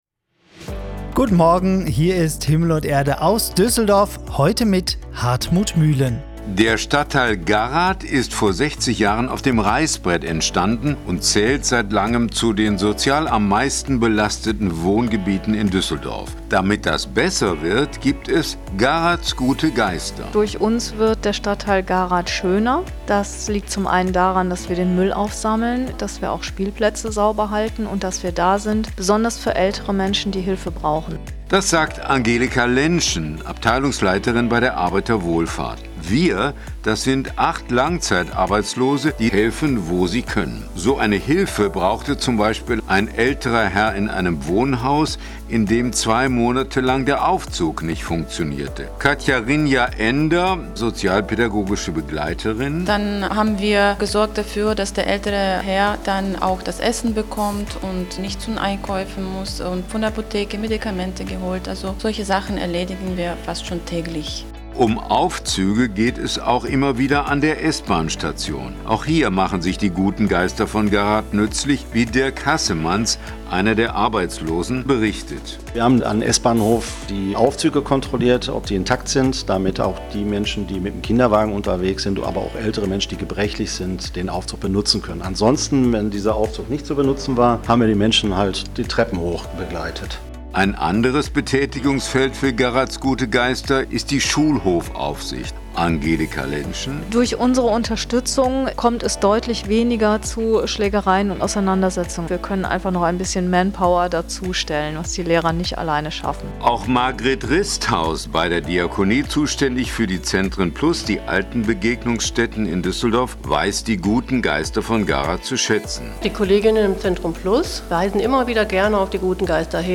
Einen aktuellen Bericht zu "Garaths gute Geister" bei Antenne Düsseldorf aus dem September 2024 können Sie